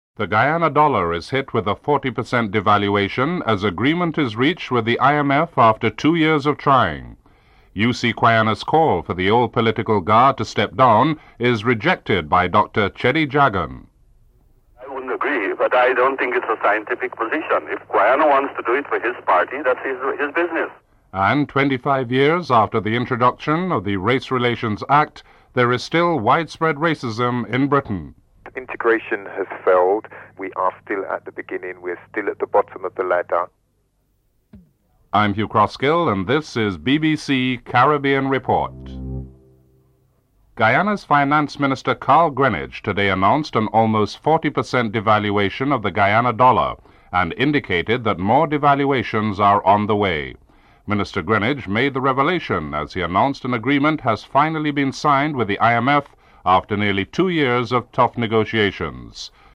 1. Headlines (00:00-00:39)